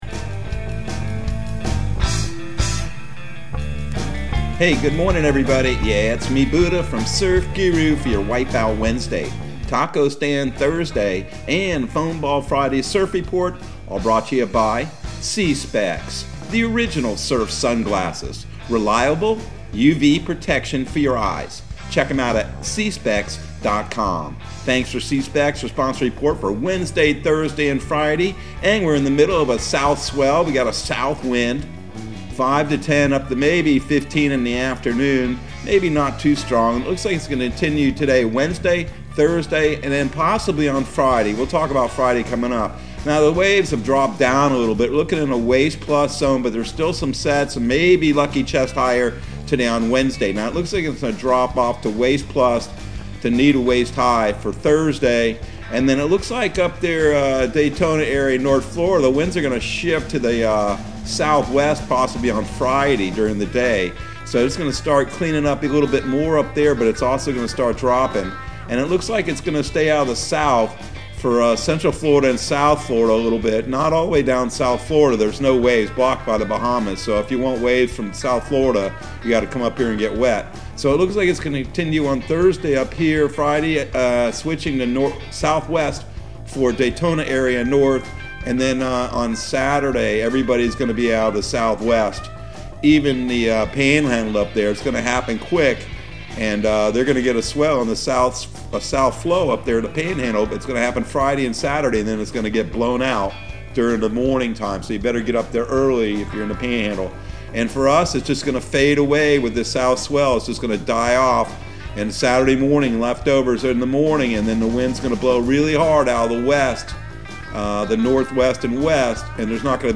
Surf Guru Surf Report and Forecast 03/09/2022 Audio surf report and surf forecast on March 09 for Central Florida and the Southeast.